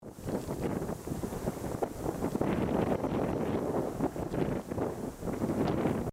FUERTE VIENTO
Tonos gratis para tu telefono – NUEVOS EFECTOS DE SONIDO DE AMBIENTE de FUERTE VIENTO
Ambient sound effects
Fuerte_viento.mp3